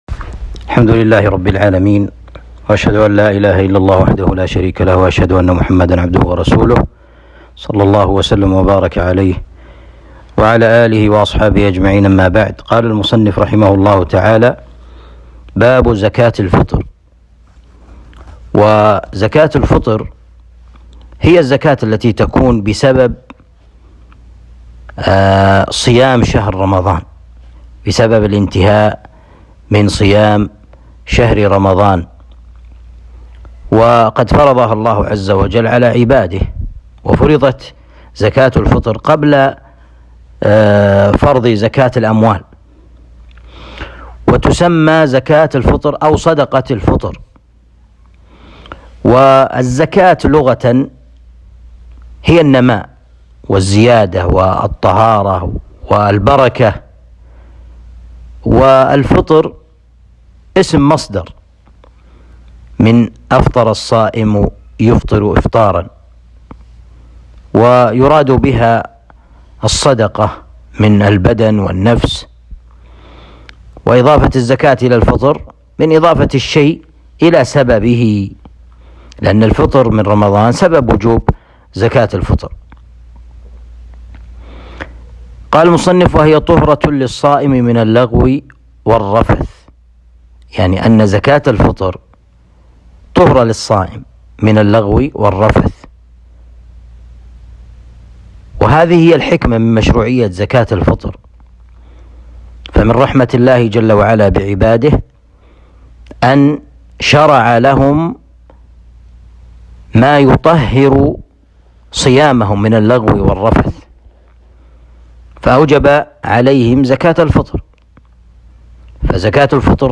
الدرس السادس والثلاثون